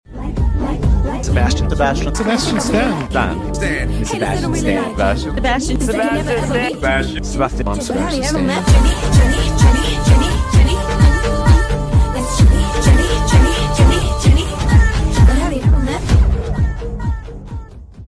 Let’s pretend that the audio glitch in the scene before „I‘m Sebastian Stan“ doesn’t exist 😃 .